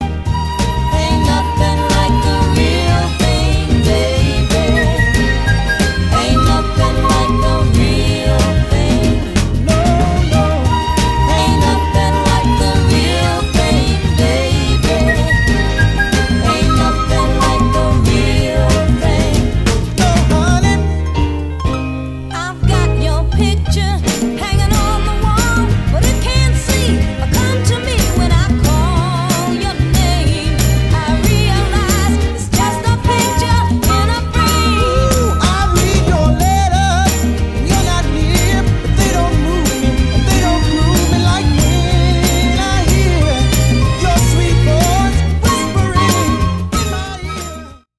et ici mon express-mix sur Mixbus